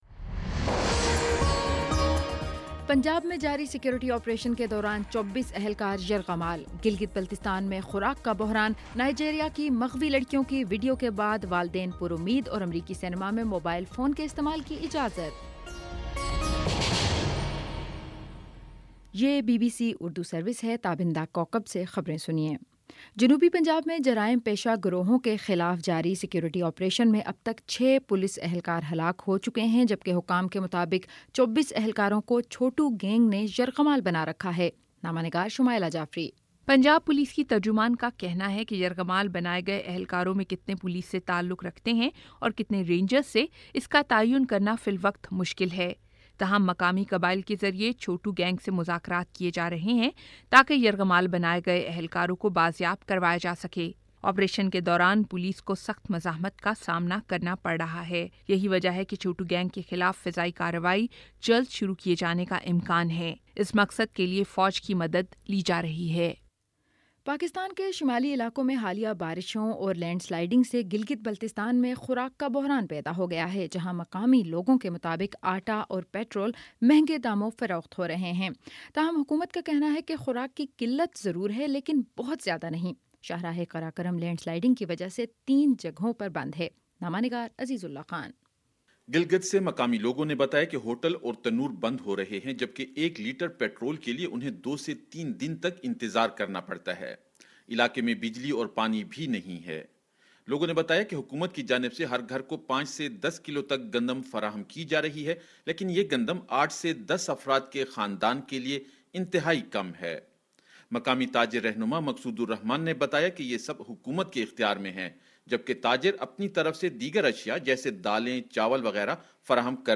اپریل 14 : شام سات بجے کا نیوز بُلیٹن